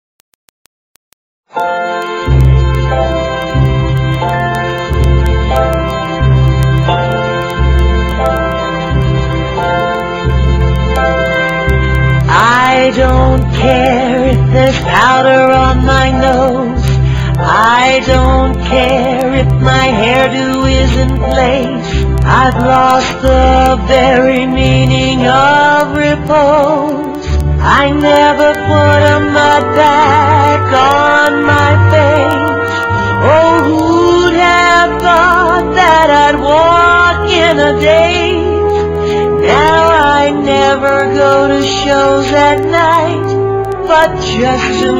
NOTE: Vocal Tracks 1 Thru 11